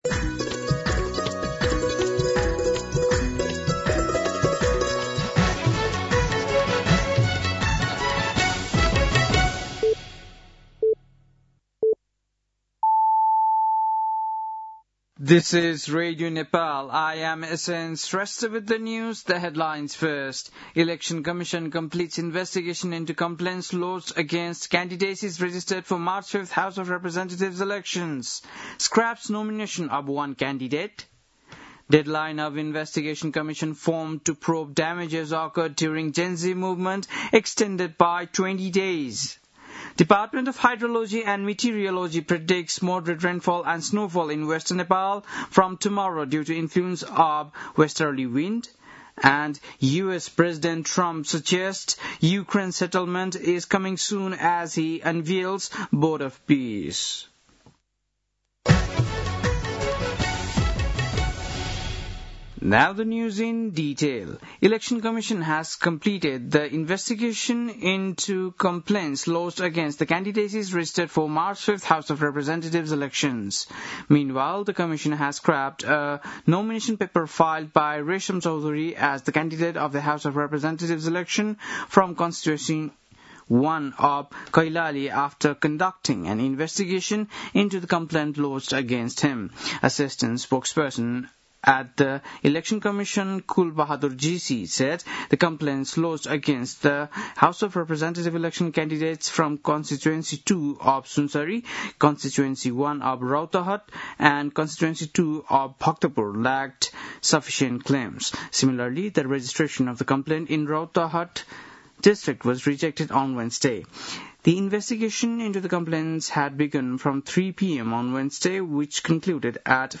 बेलुकी ८ बजेको अङ्ग्रेजी समाचार : ८ माघ , २०८२
8-pm-news-.mp3